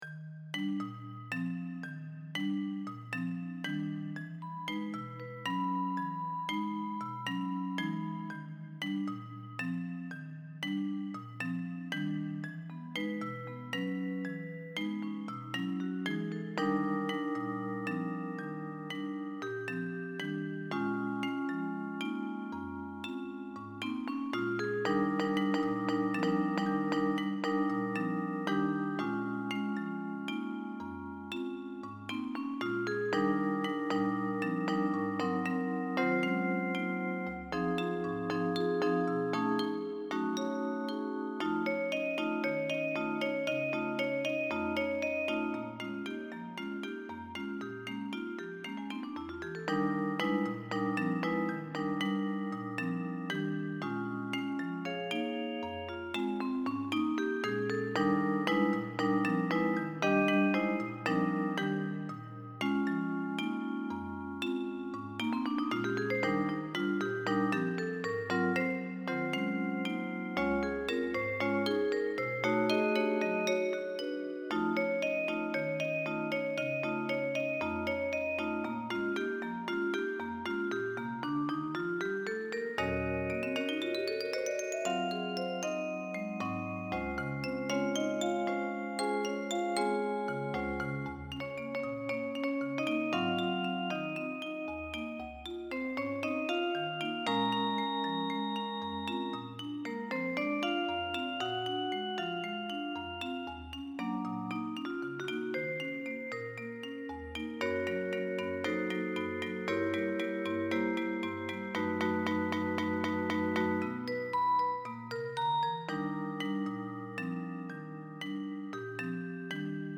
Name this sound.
Instrumentation: percussions